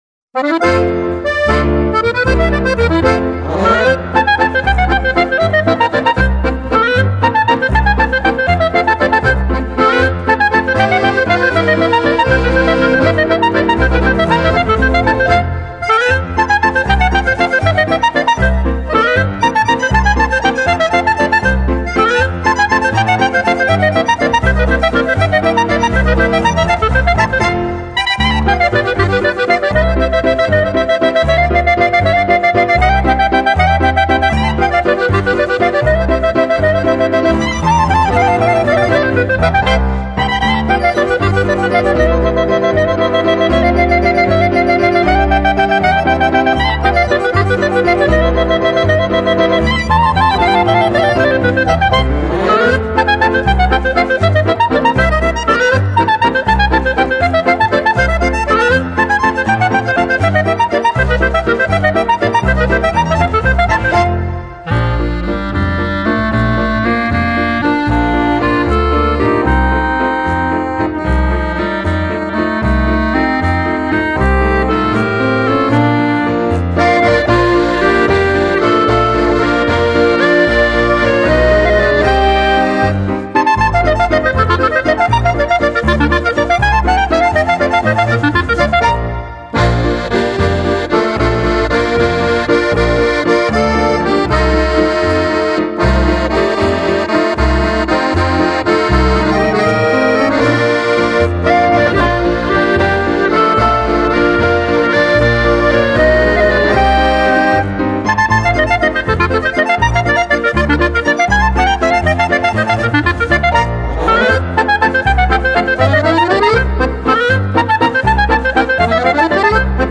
Ländler.